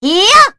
Sonia-Vox_Attack3.wav